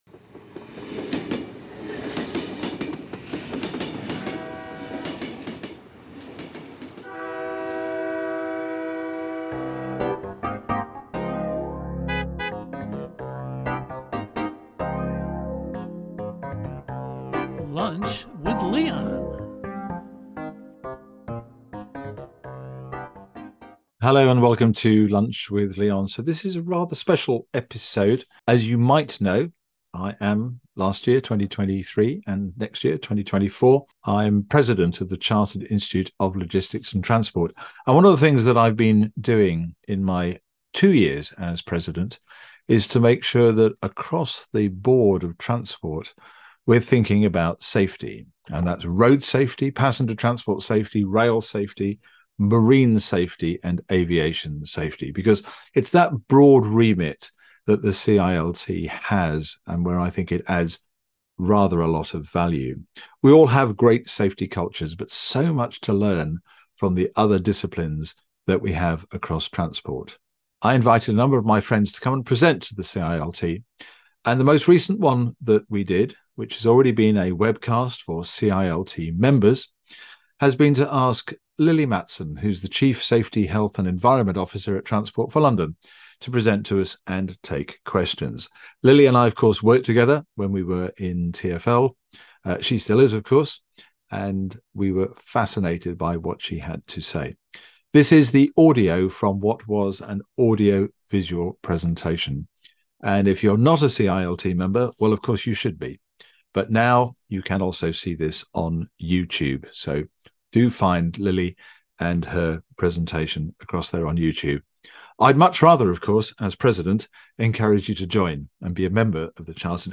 These webcasts are seen by CILT members live in a webcast.